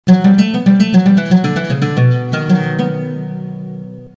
Asagida Dinlediginiz Sample Sesleri direk Orgla Calinip MP3 Olarak Kayit edilmistir
Ud Sample